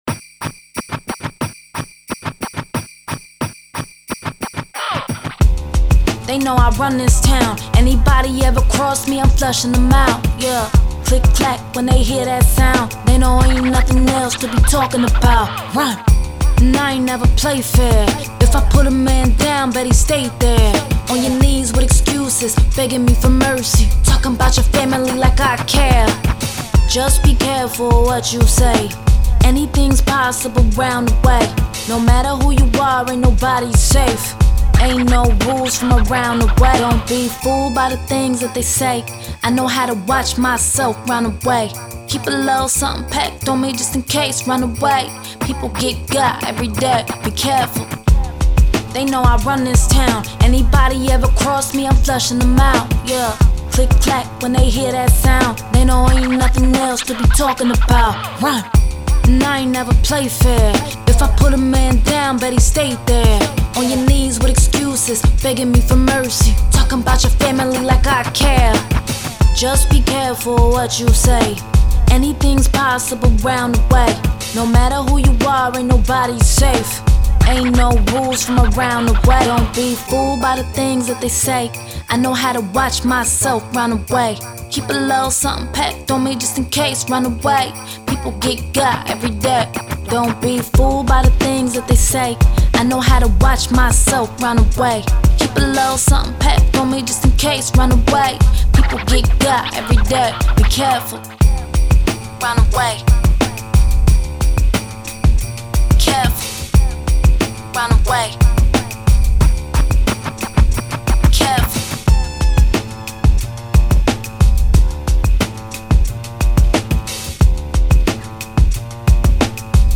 Hip Hop, 80s, 90s
D Minor